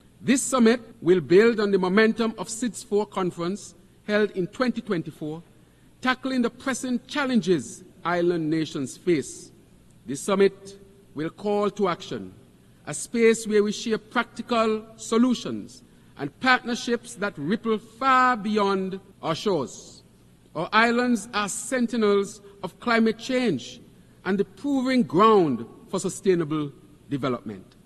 This was Prime Minister-Dr. Terrance Drew during the UN General Assembly as he provided information on the summit: